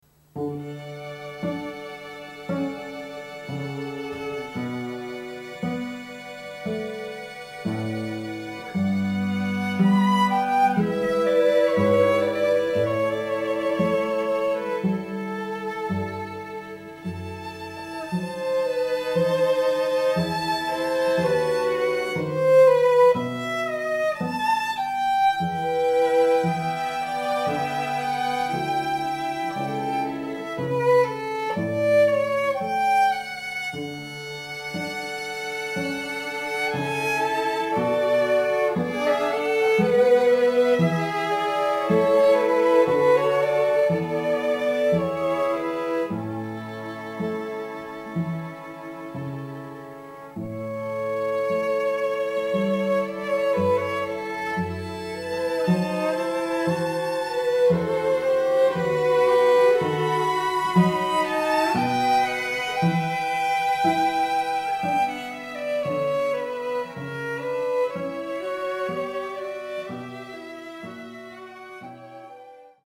Comprised of four professional musicians, this highly-respected string quartet have been performing together for over 20 years, and the sophisticated sounds of their beautifully-harmonised strings create a magical, romantic atmosphere for wedding ceremonies or receptions.
• Professional string quartet